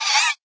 land_idle1.ogg